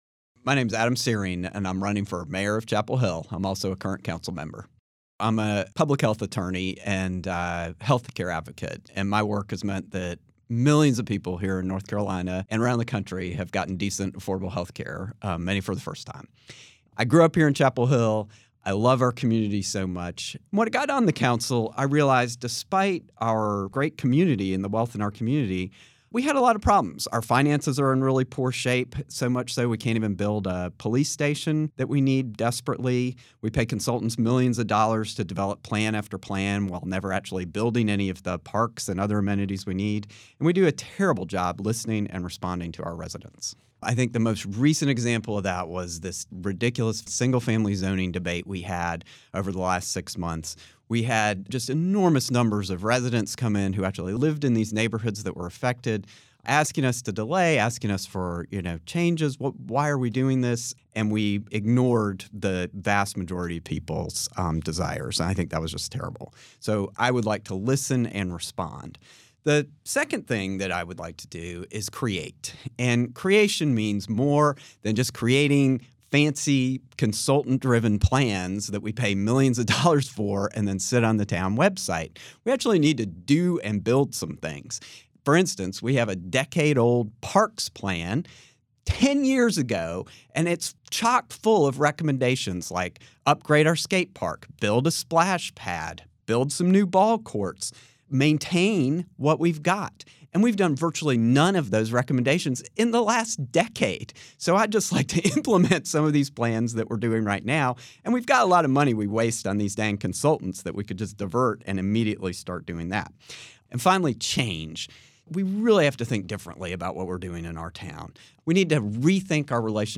During this local election season, 97.9 The Hill and Chapelboro spoke with candidates for races representing Chapel Hill, Carrboro and Hillsborough. Each answered the same set of questions regarding their decision to run for elected office, their background in the community and what they wish for residents to think of when voting this fall. Their answers (lightly edited for clarity and brevity) are shared here, as well as links to their respective campaign websites or pages.